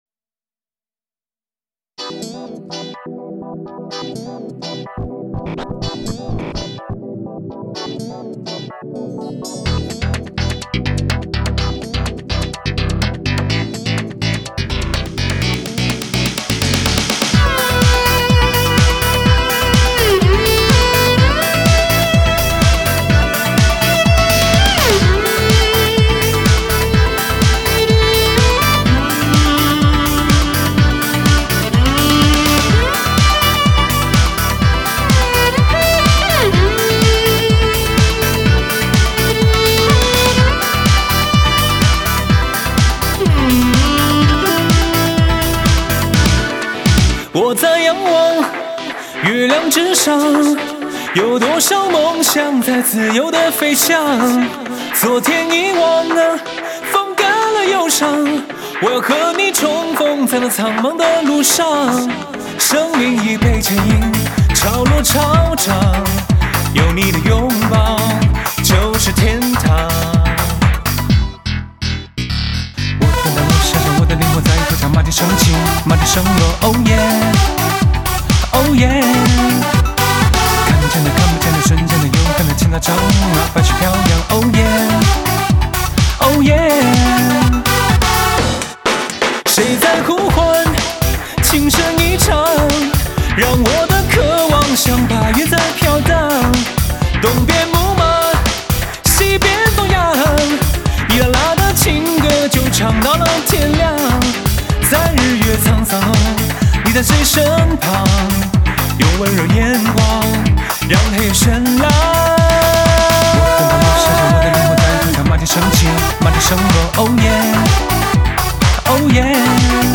京剧花脸 蒙古风韵 Rap说唱 民族音乐 西洋音乐 电子音效
大胆创新迪士高动感发烧精品